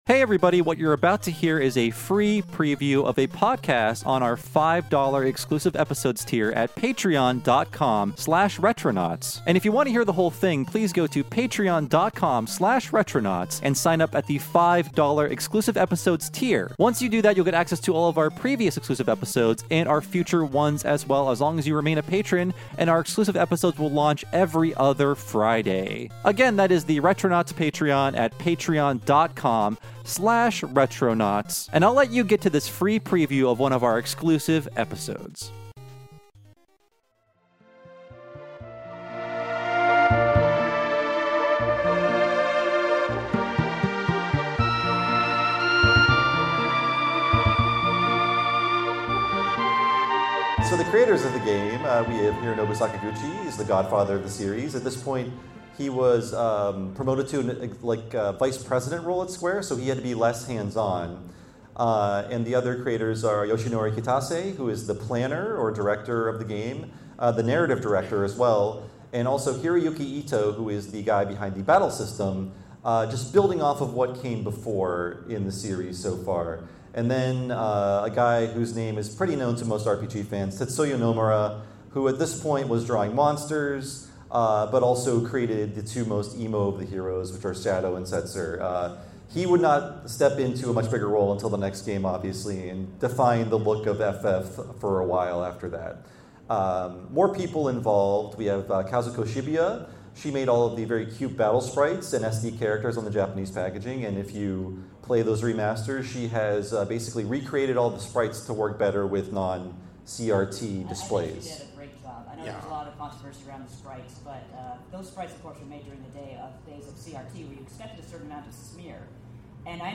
Episode 613 Preview: Final Fantasy VI (Live From Midwest Gaming Classic 2024)